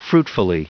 Prononciation du mot fruitfully en anglais (fichier audio)
Prononciation du mot : fruitfully